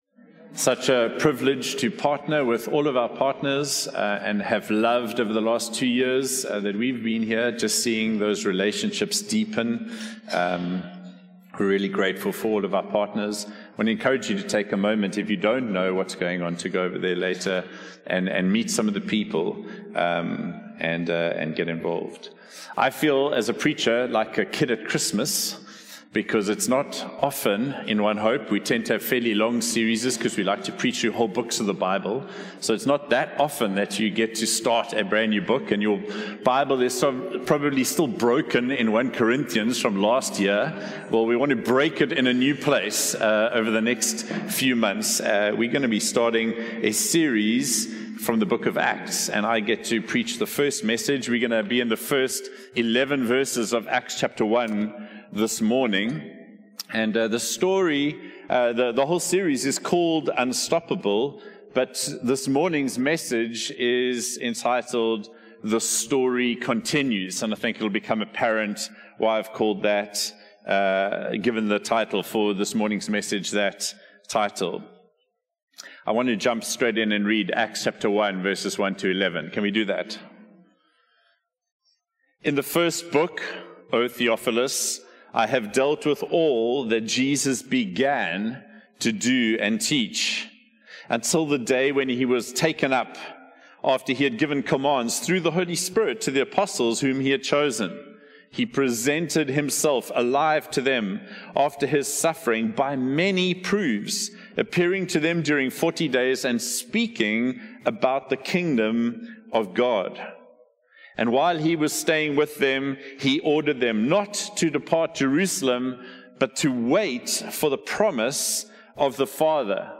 One-Hope-Sermon-1-March-2026.mp3